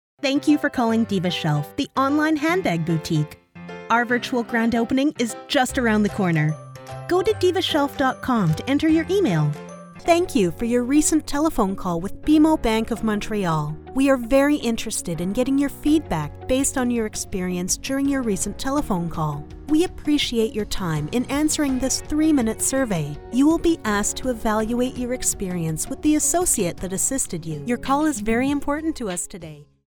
She works from a professional grade home studio to provide her clients with the highest quality audio as quickly as possible.
Records voiceovers in: American
Telephone Message